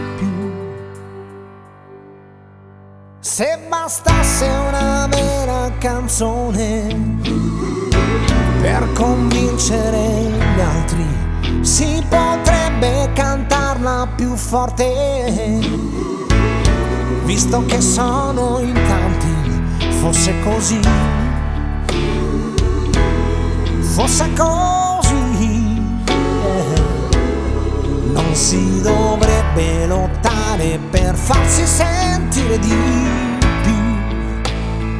Жанр: Поп